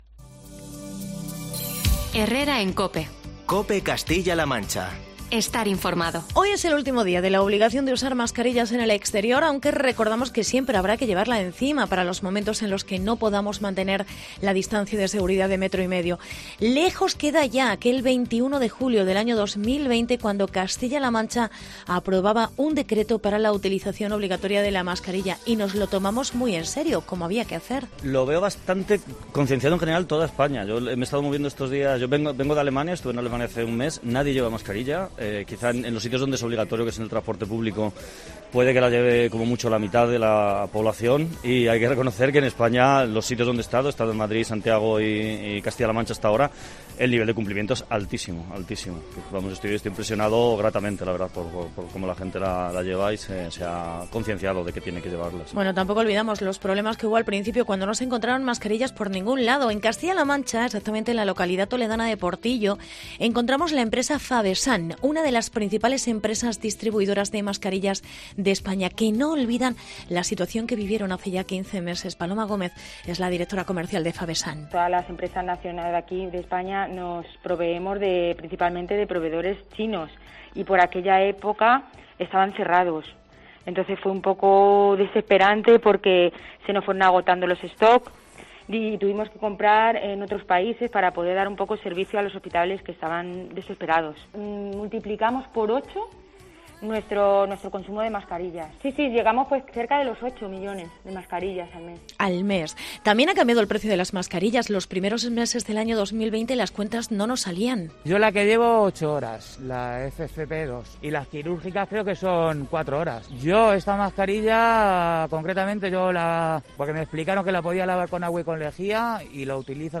Reportaje sobre la evolución de las mascarillas